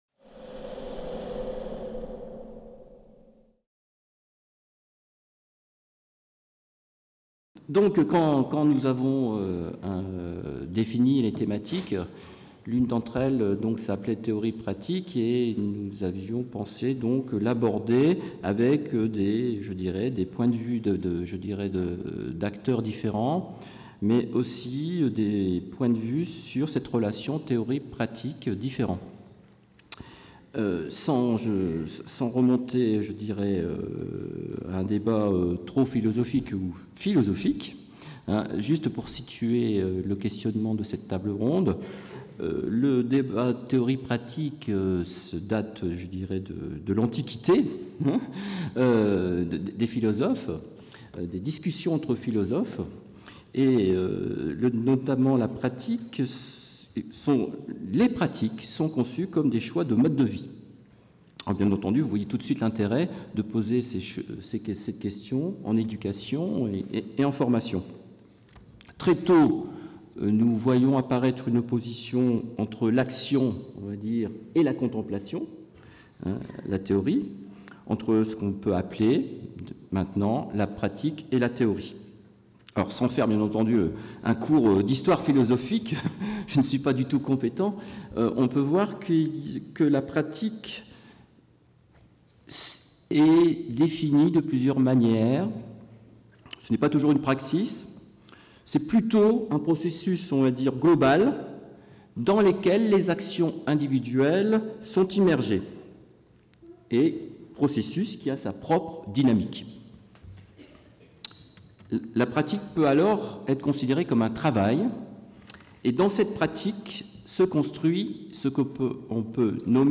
Mercredi 18 octobre 2017 Table ronde : Théorie-pratique (Amphithéâtre Pierre Daure)